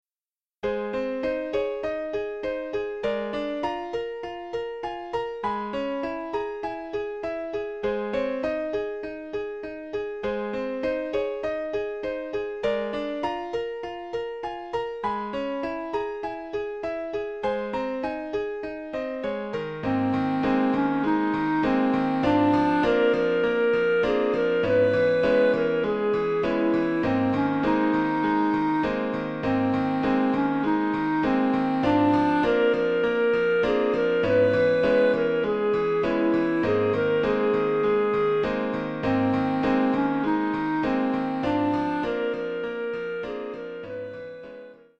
Sample from the Backing CD